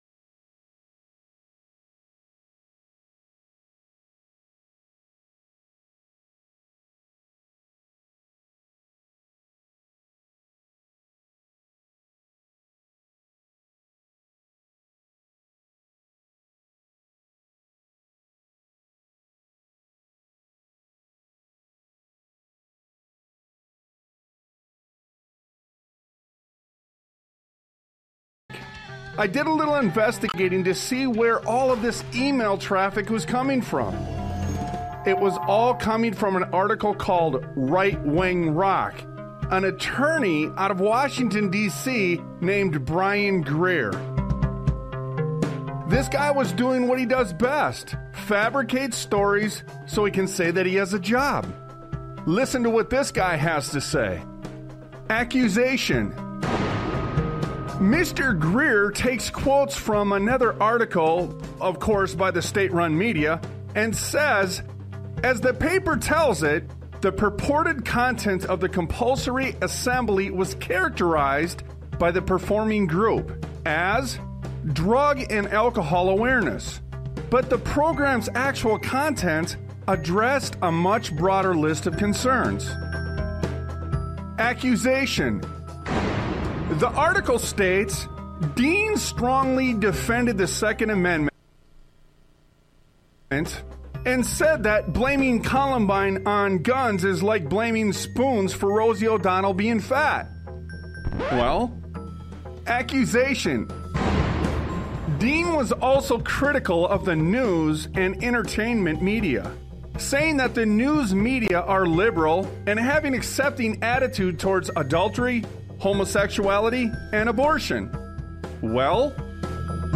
Talk Show Episode, Audio Podcast, Sons of Liberty Radio and Oh, It Gets Worse on , show guests , about It Gets Worse, categorized as Education,History,Military,News,Politics & Government,Religion,Christianity,Society and Culture,Theory & Conspiracy